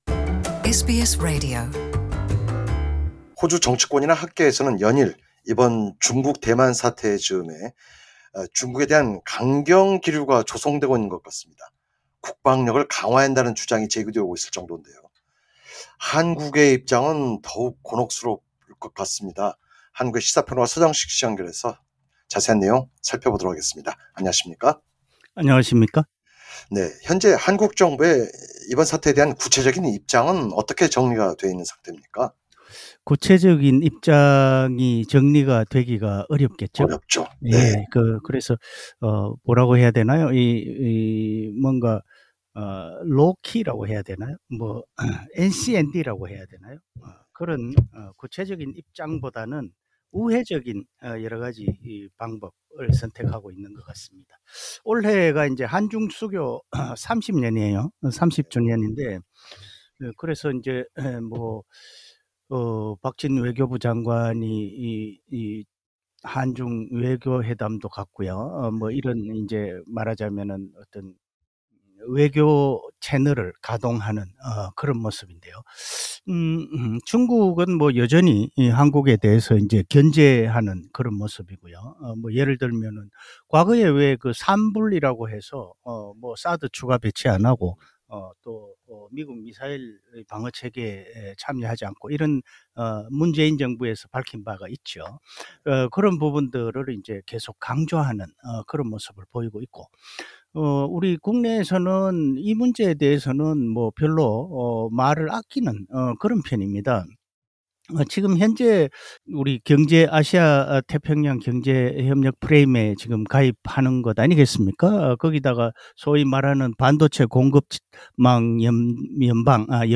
시사 평론가